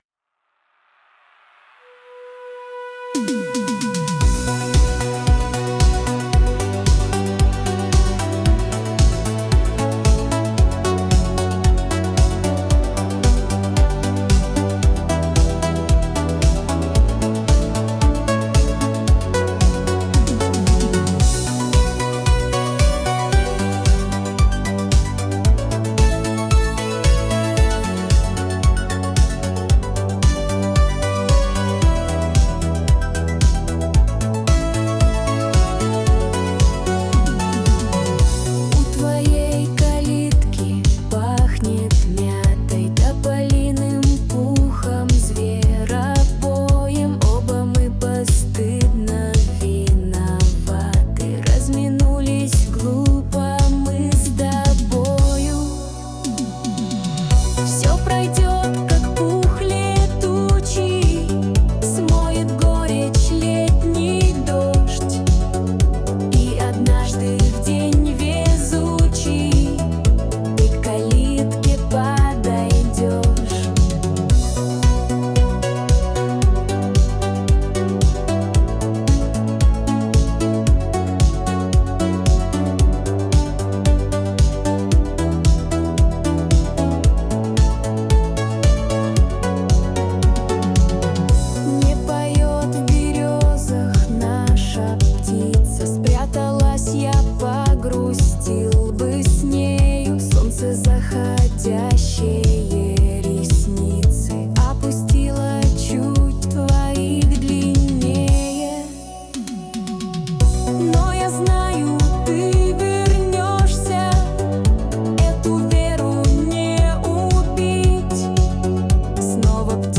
Качество: 320 kbps, stereo
Клубная музыка, Новинки, Танцевальная музыка